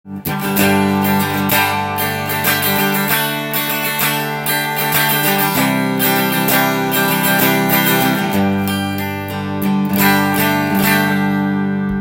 試しにこのギターを弾いてみました。
もの凄く音が小さいです！磁力がかなり落ちているピックアップを載せているいるようなので
音が小さくなりますがその分　枯れた音がするというギターです。